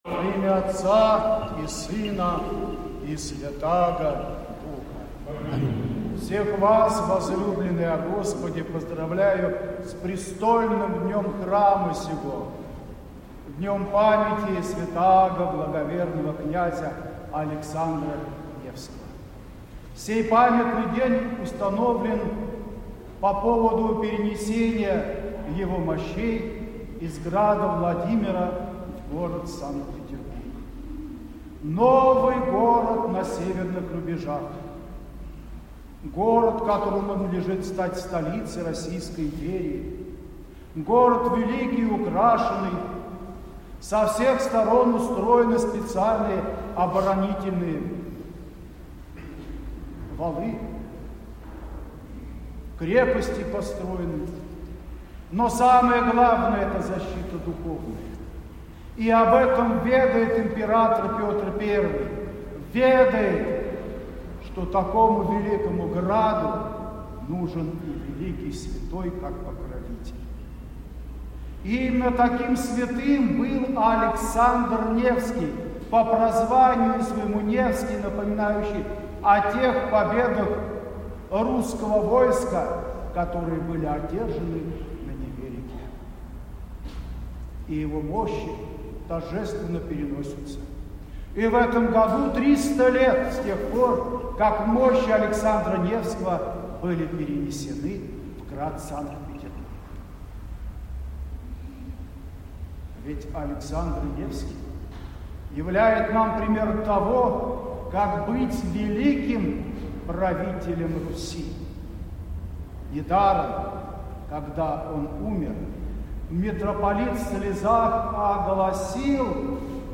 Архиерейское богослужение в Александро-Невском соборе
В завершение митрополит обратился к священнослужителям и прихожанам Александро-Невского собора с архипастырским словом.